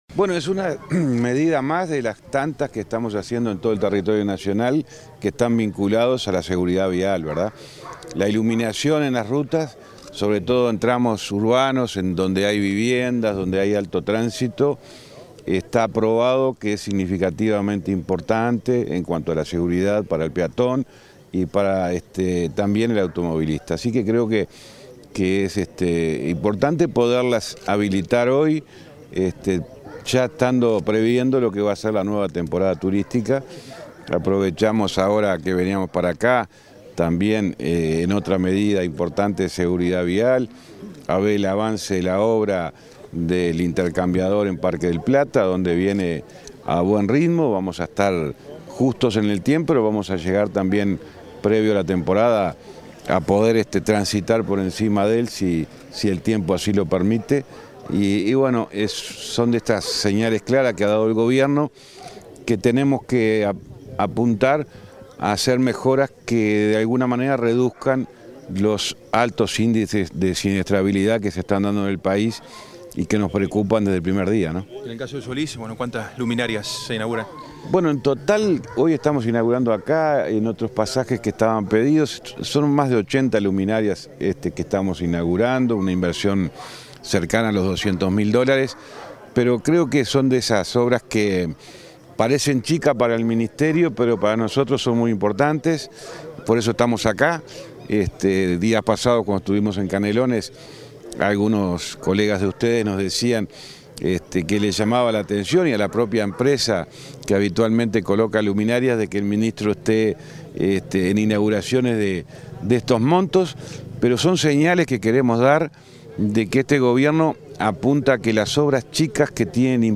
Declaraciones a la prensa del ministro del MTOP, José Luis Falero
Declaraciones a la prensa del ministro del MTOP, José Luis Falero 15/11/2023 Compartir Facebook X Copiar enlace WhatsApp LinkedIn Tras participar en la inauguración, este 14 de noviembre, de luminarias en el balneario Solís, del departamento de Maldonado, instaladas por el Ministerio de Transporte y Obras Pública (MTOP), el ministro José Luis Falero efectuó declaraciones a la prensa.